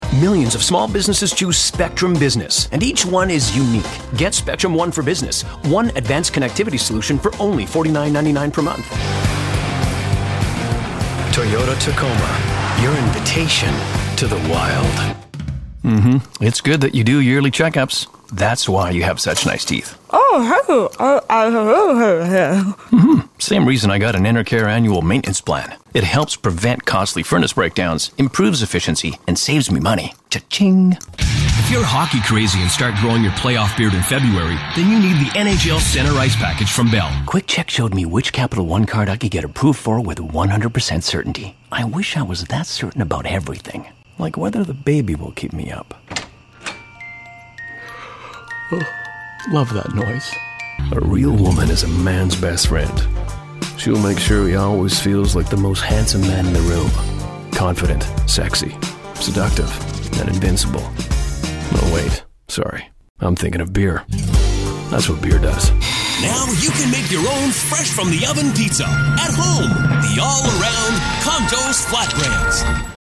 Male
English (Canadian)
Commercial Demo
Soundproof studio
RODE NT1000 & Sennheiser MKH416